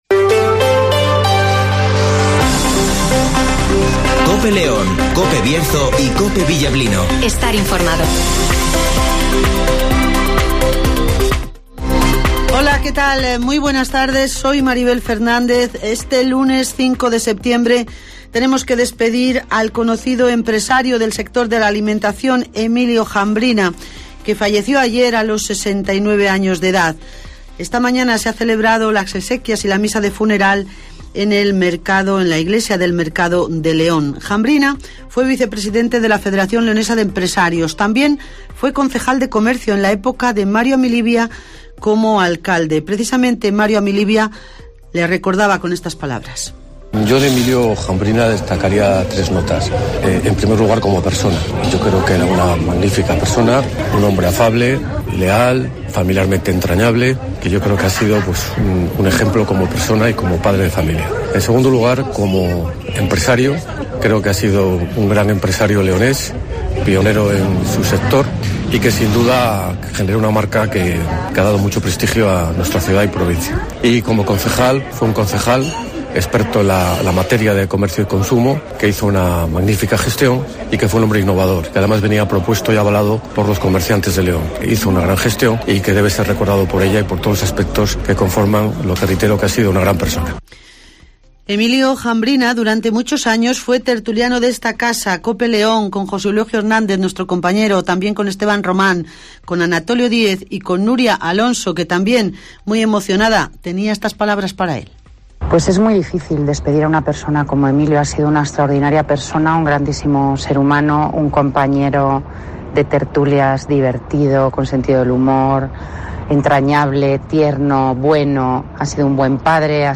Escucha aquí las noticias con las voces de los protagonistas.